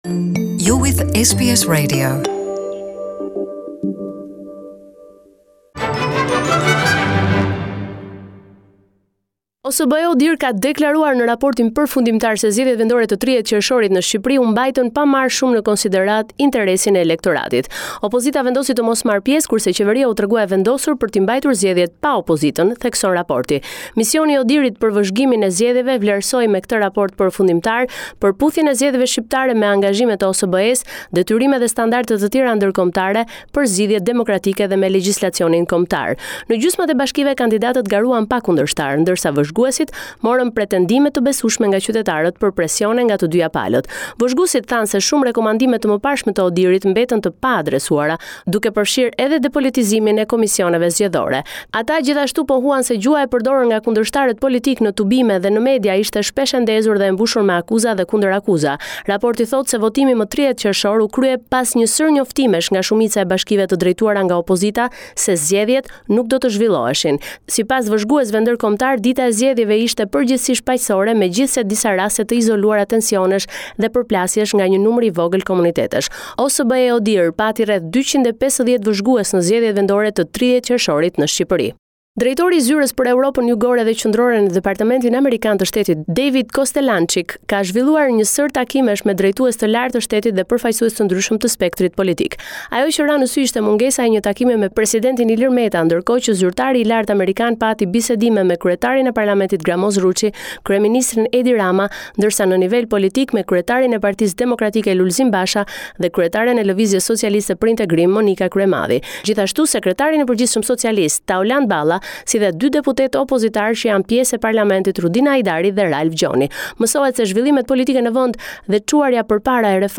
This is a report summarising the latest developments in news and current affairs in Albania.